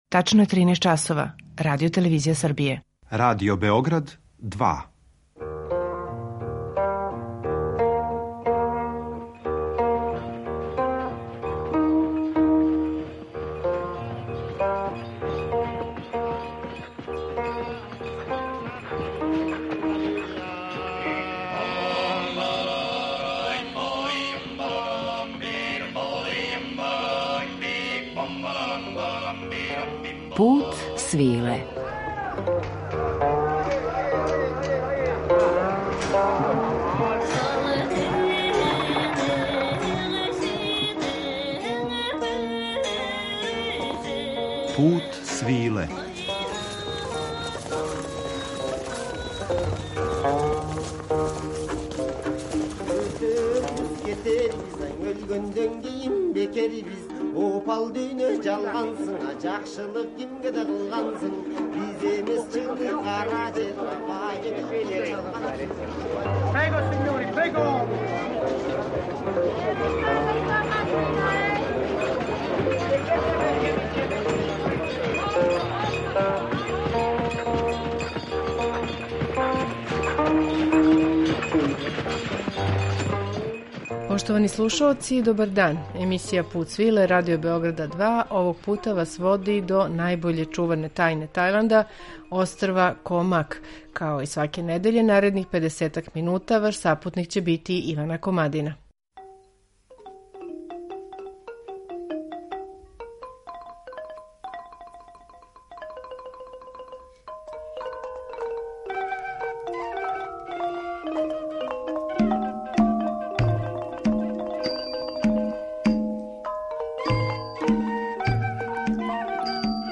Музички рам овог пута чиниће дела из репертоара класичне и дворске музике Тајланда, између осталог и композиције краља Праџадипока Раме Седмог.